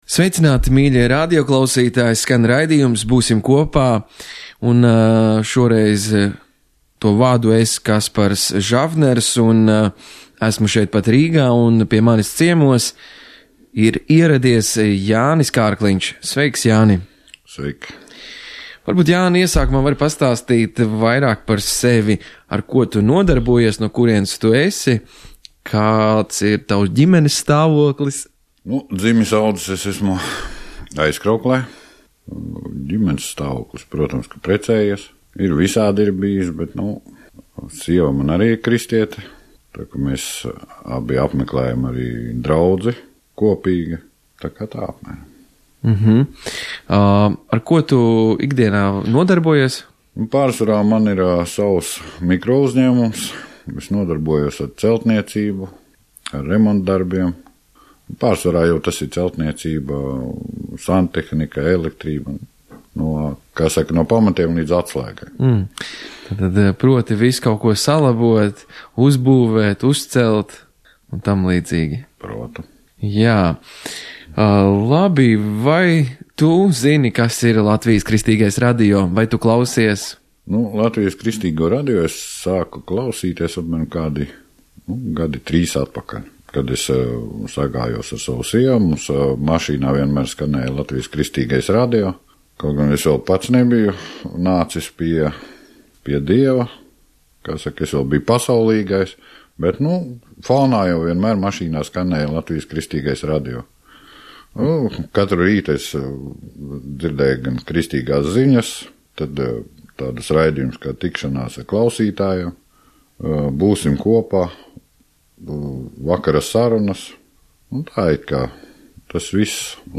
raidījuma viesis